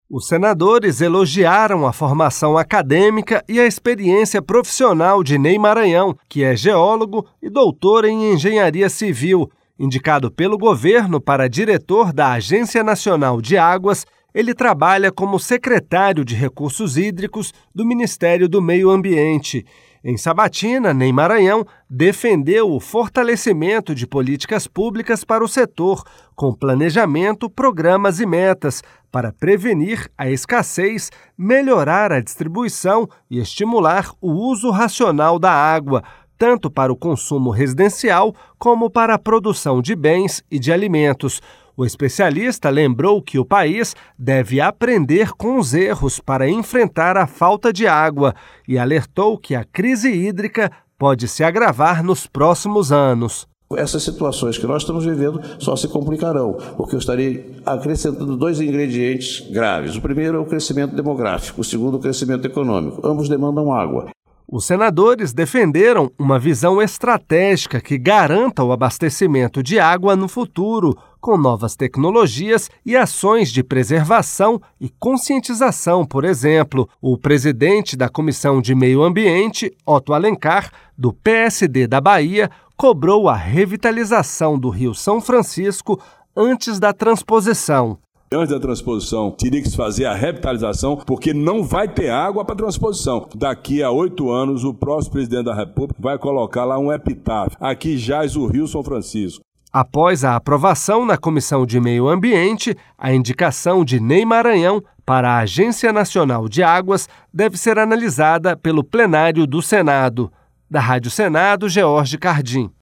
— Rádio Senado Crise hídrica no Brasil pode se agravar, avalia indicado para ANA